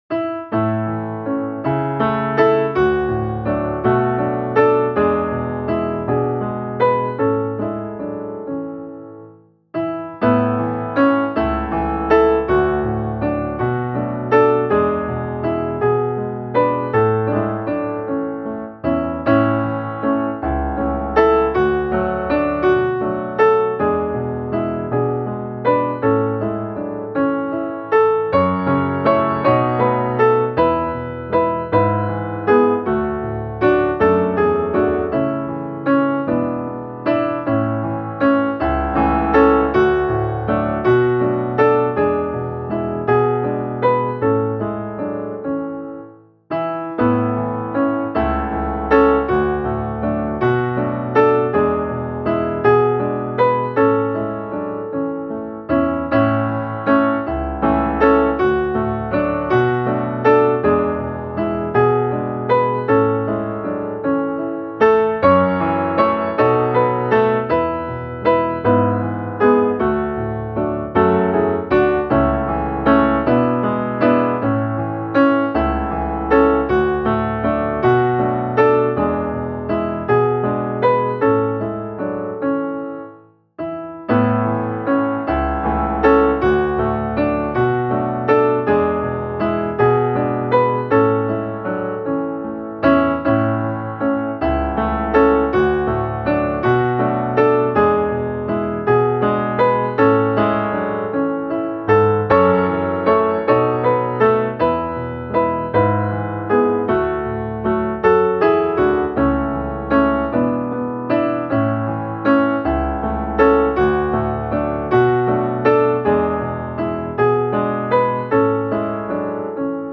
O sällhet stor, som Herren ger - musikbakgrund